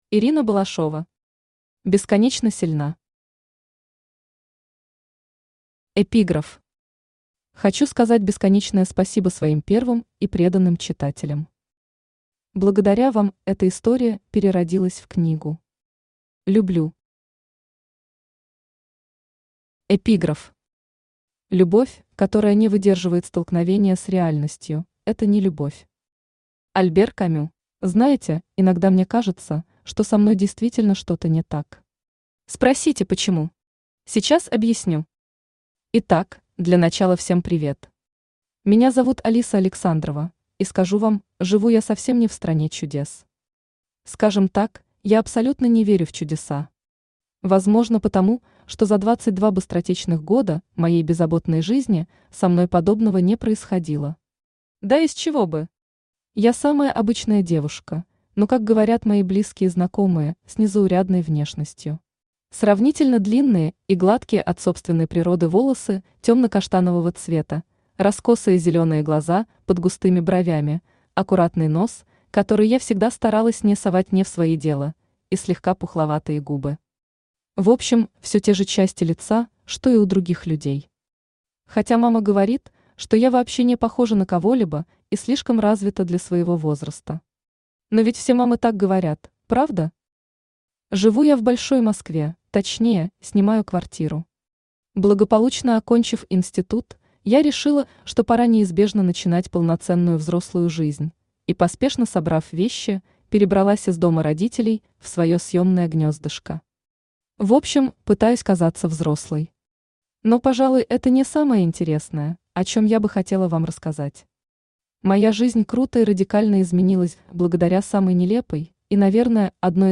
Аудиокнига Бесконечно сильна | Библиотека аудиокниг
Aудиокнига Бесконечно сильна Автор Ирина Балашова Читает аудиокнигу Авточтец ЛитРес.